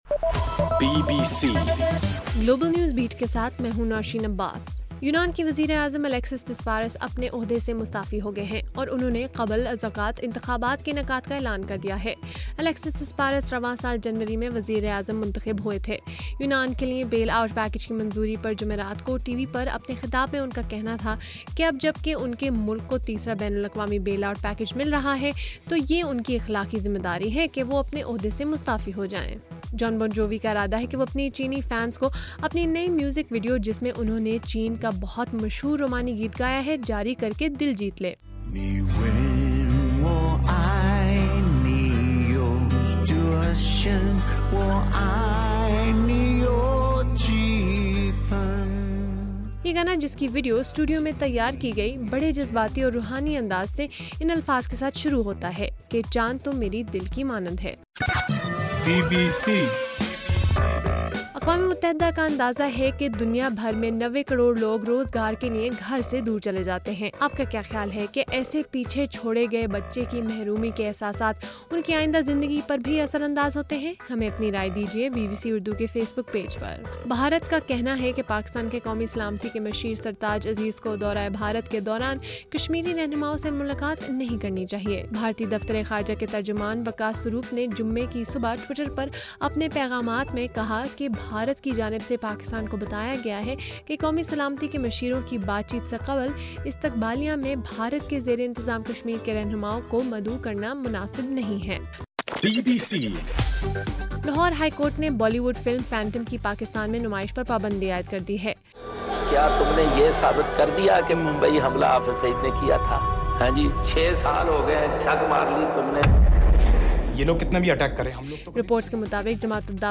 اگست 22: صبح 1 بجے کا گلوبل نیوز بیٹ بُلیٹن